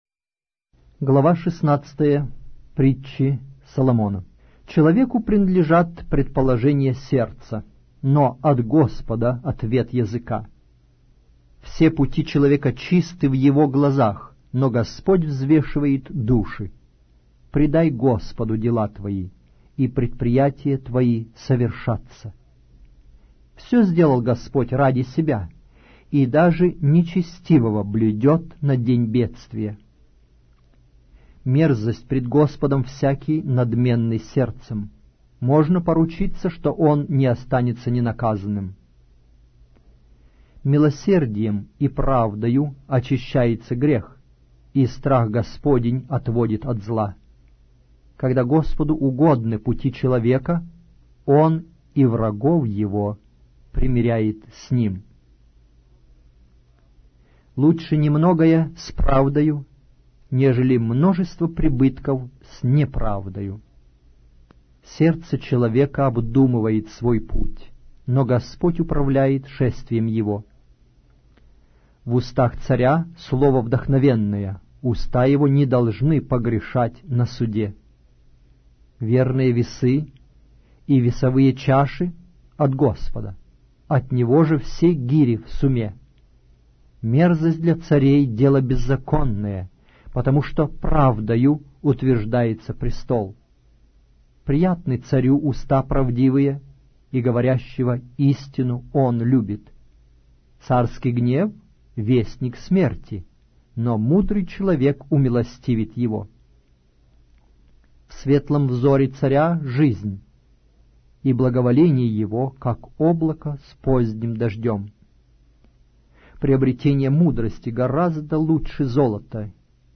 Аудиокнига: Притчи Соломона